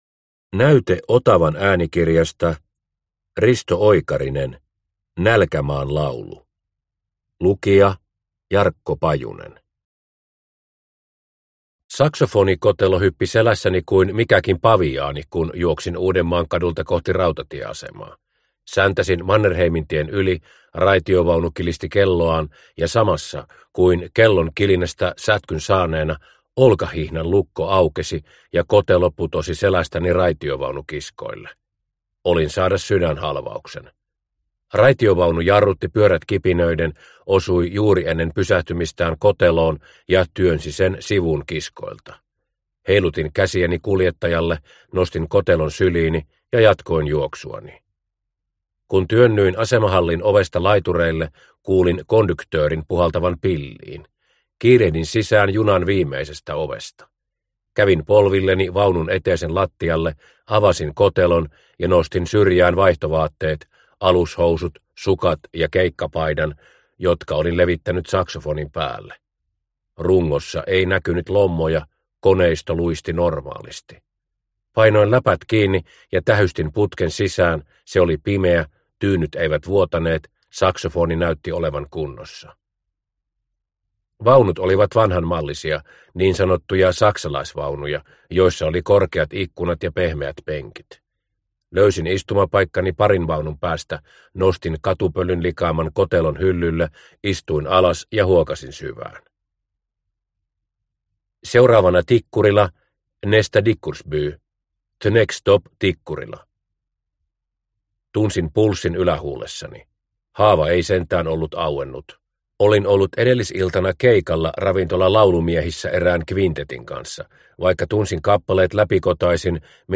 Nälkämaan laulu – Ljudbok – Laddas ner